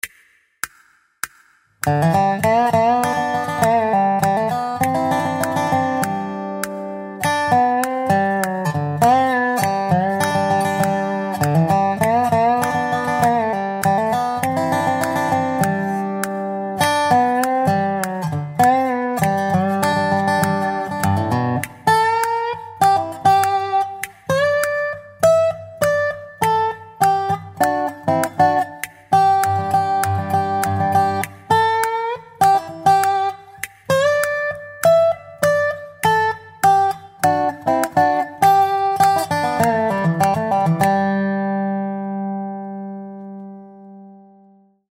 Nástroj: Dobro
Tónina: G
Nízká Styl: Bluegrass Ladění